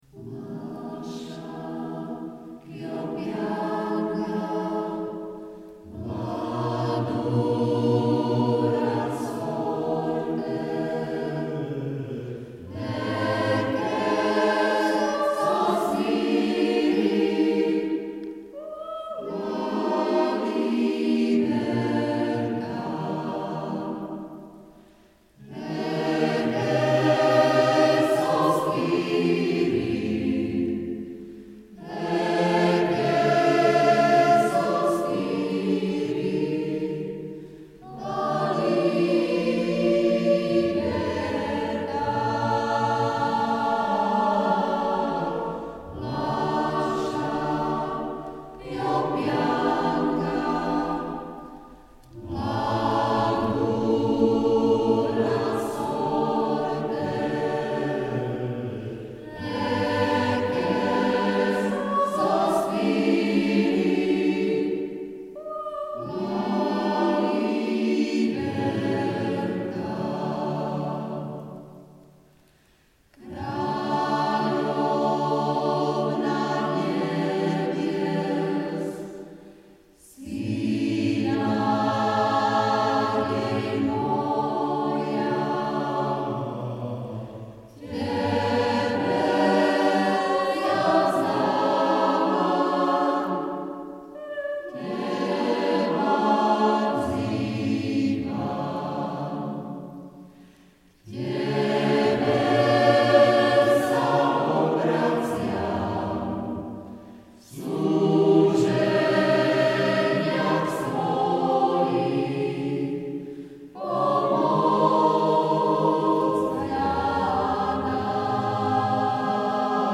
Vypočujte si :: Spevácky zbor